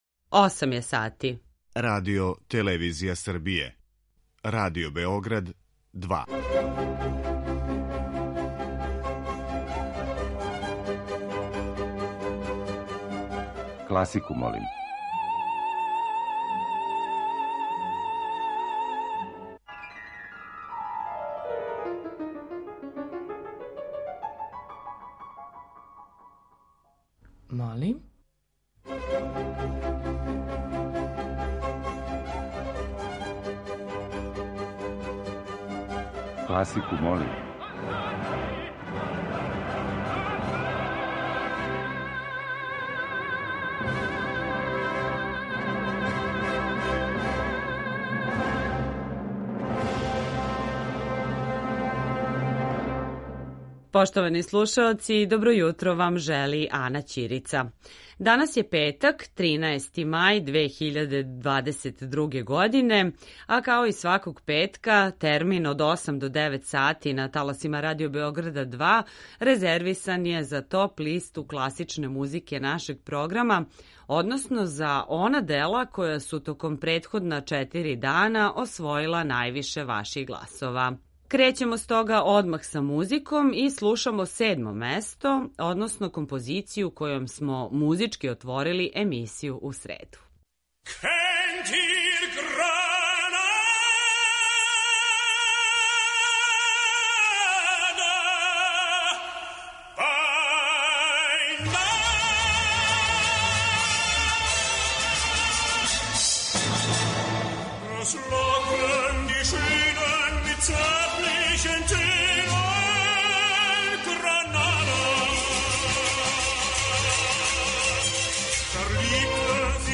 После сабирања гласова које смо примили од понедељка до четвртка, емитујемо топ-листу класичне музике Радио Београда 2, односно композиције које су се највише допале слушаоцима.